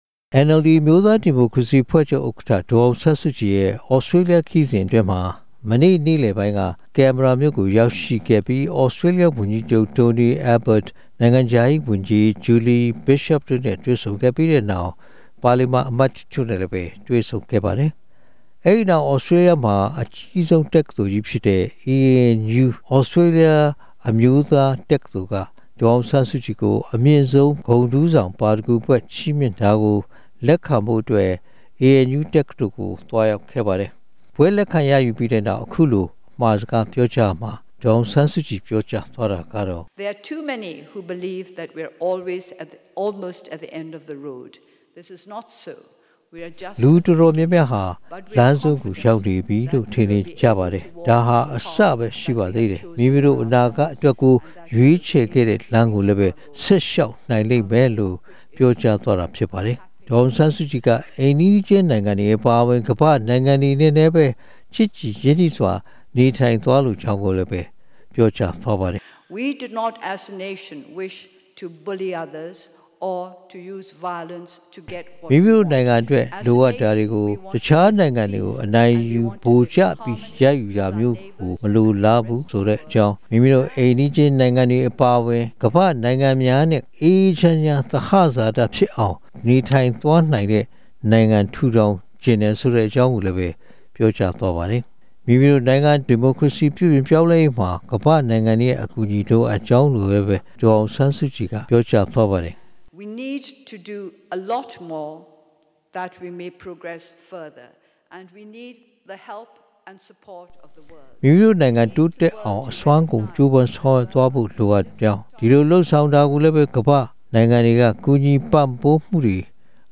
ဘွဲ့လက်ခံရယူတဲ့ အခမ်းအနားမှာ ဒေါ်အောင်ဆန်း စုကြည်က မြန်မာနိုင်ငံ ဒီမိုကရေစီ ပြုပြင်ပြောင်းလဲရေးနဲ့ ပတ်သက်လို့ အခုလို ထည့်သွင်းပြောဆိုခဲ့ပါတယ်။